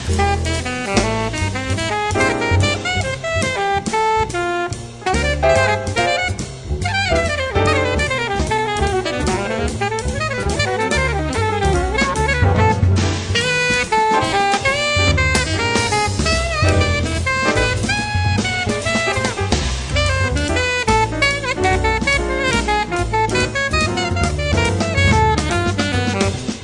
The Best In British Jazz
Recorded Eastcote Studios, West London 2006